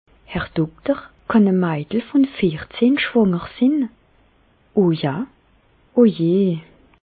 Expressions populaires
Bas Rhin
Ville Prononciation 67
Reichshoffen